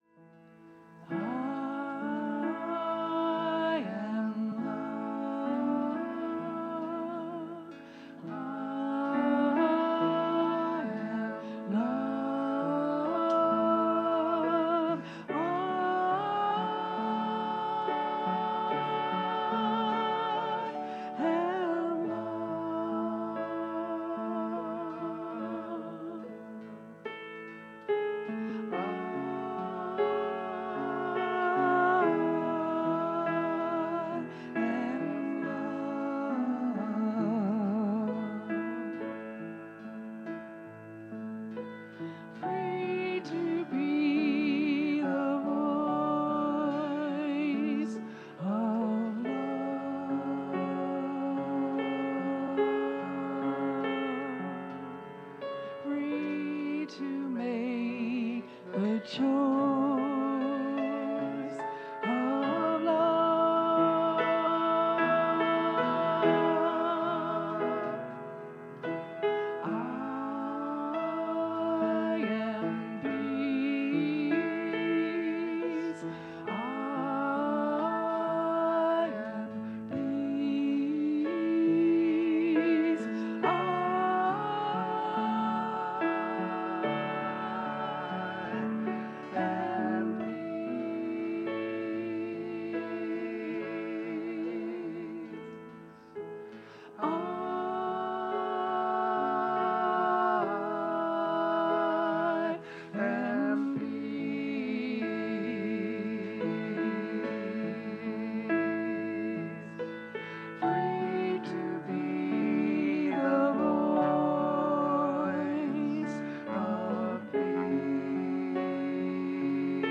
The audio recording (below the video clip) is an abbreviation of the service. It includes the Meditation, Message, and Featured Song.